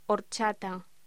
Locución: Horchata